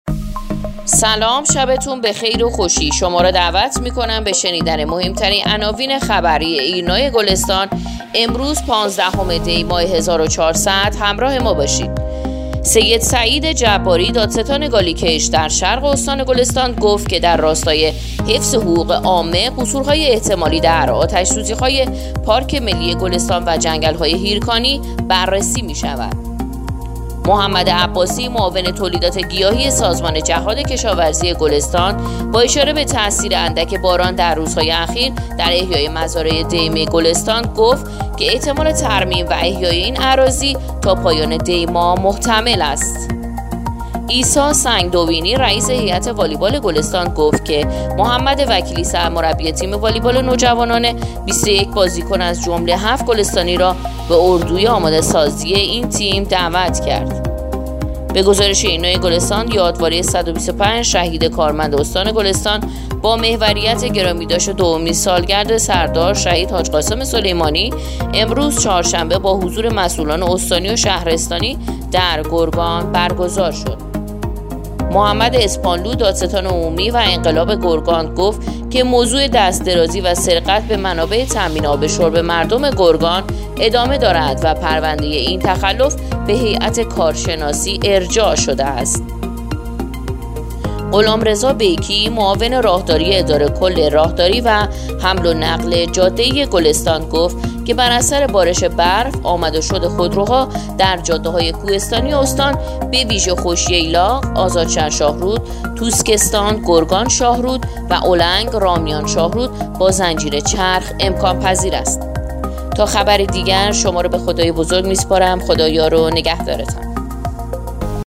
پادکست/ اخبار شامگاهی پانزدهم دی ماه ایرنا گلستان